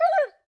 monster / wolf / damage_2.wav
damage_2.wav